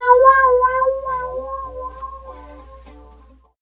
70 GTR 2  -L.wav